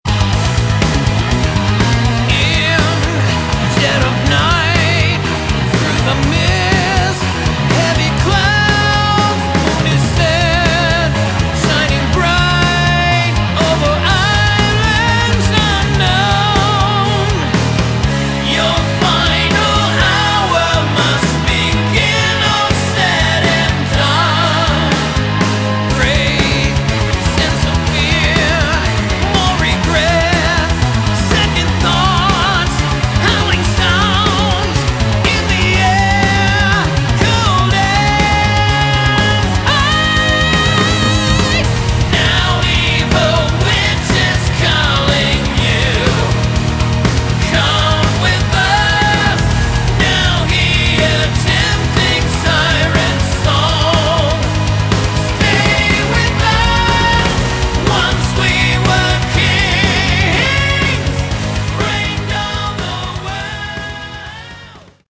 Category: Melodic Metal
lead and backing vocals
guitars, bass, keyboards
drums